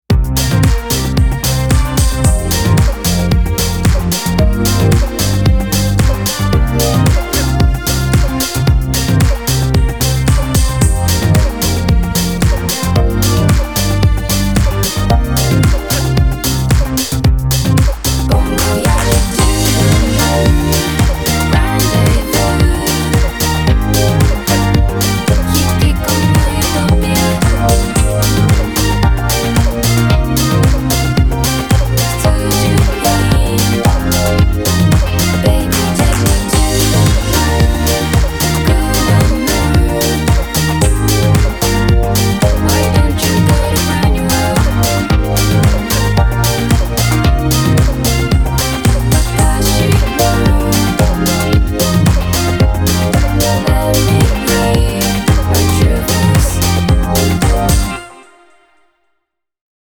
今回のオーディションのために制作されたオリジナル楽曲です。